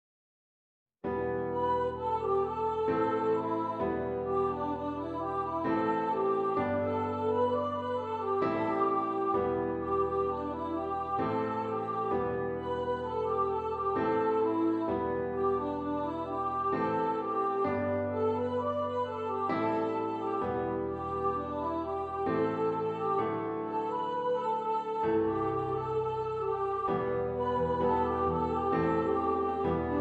E Minor
Adagio